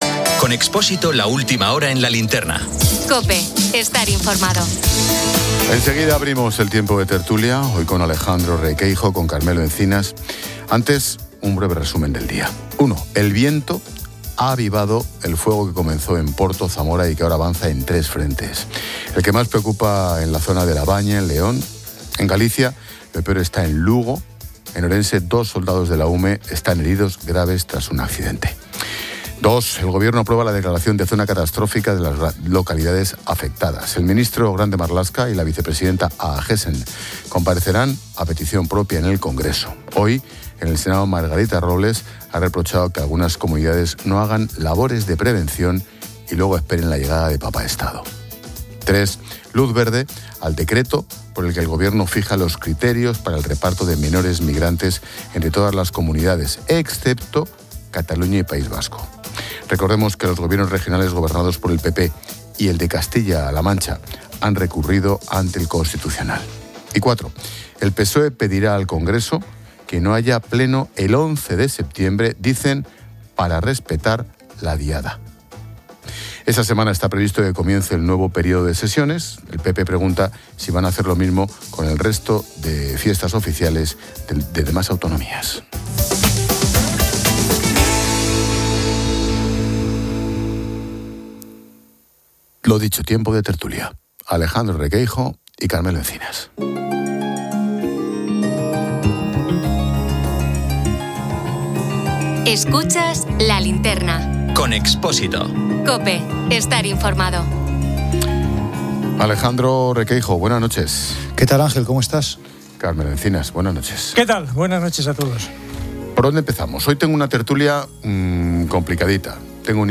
El audio inicia con un resumen de noticias sobre incendios en España y la declaración de zona catastrófica, la crítica de Margarita Robles a comunidades autónomas y el decreto de reparto de menores migrantes. También se menciona la propuesta del PSOE de suspender el pleno del Congreso el 11 de septiembre por la Diada.